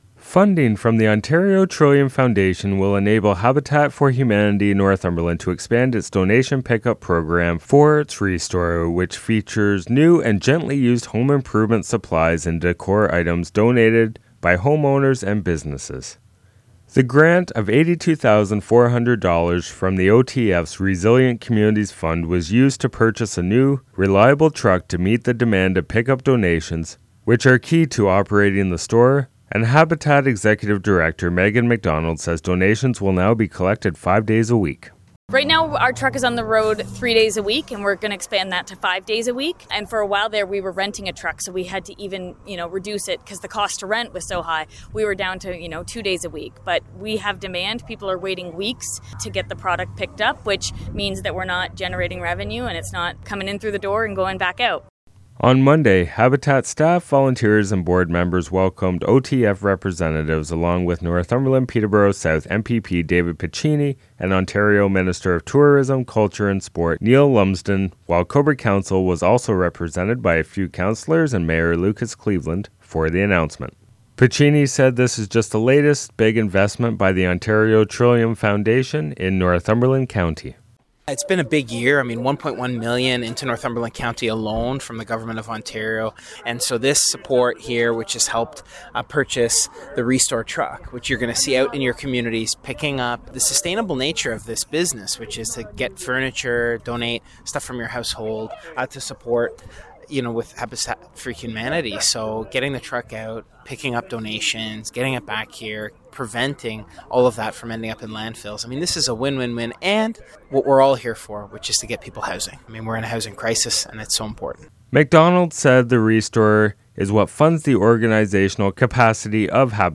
July24-Habitat-funding-report.mp3